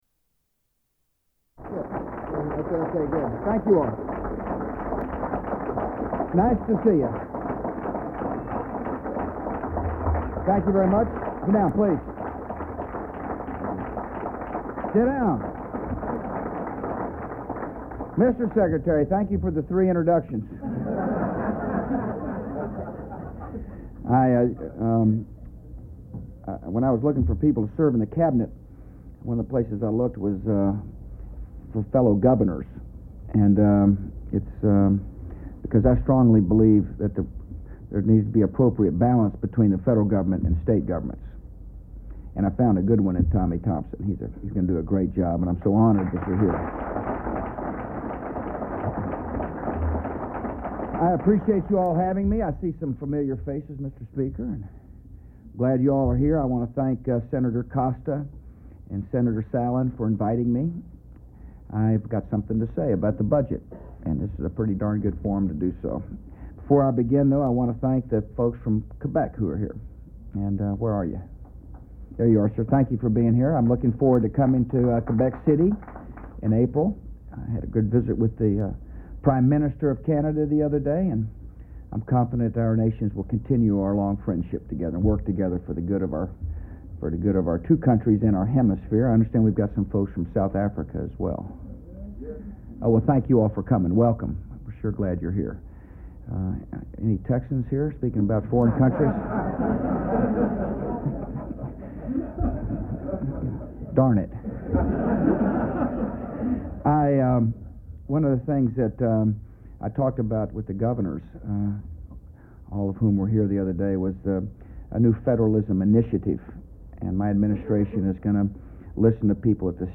U.S. President George W. Bush addresses the National Conference of Legislatures